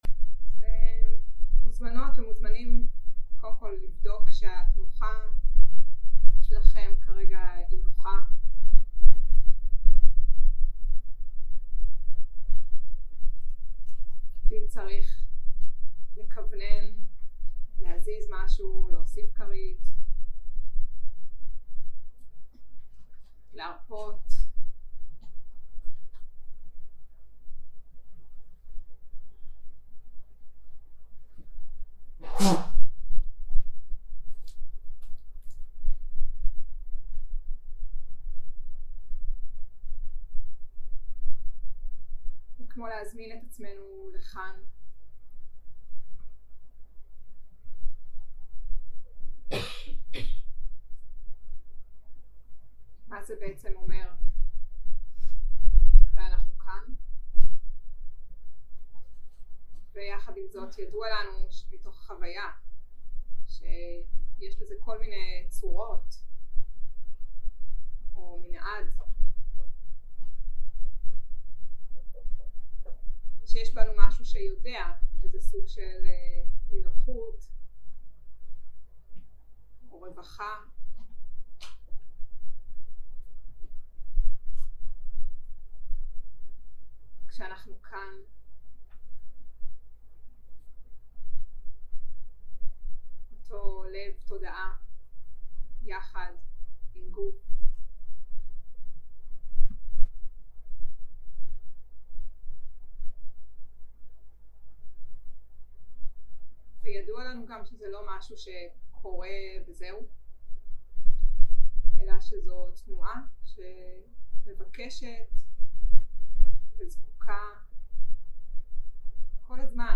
יום 2 - הקלטה 2 - צהרים - מדיטציה מונחית
סוג ההקלטה: מדיטציה מונחית